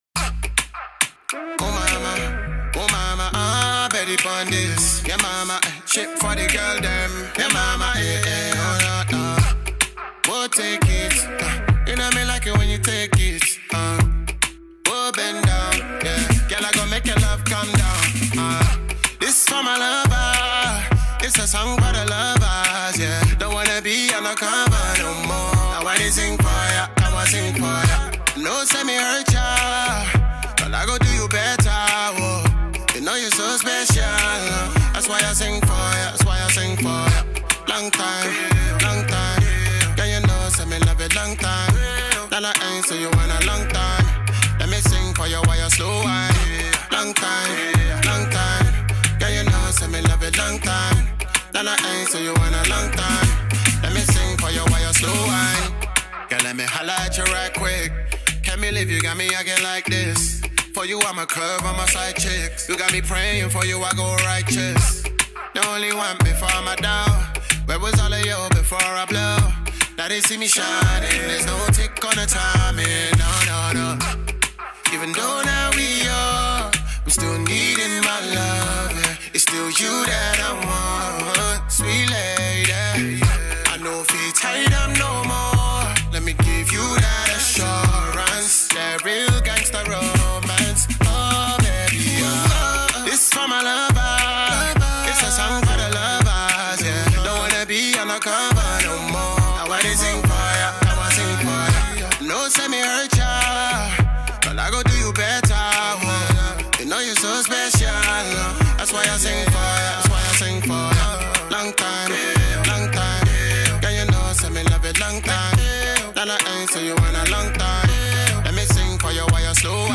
The British based Nigerian singer/producer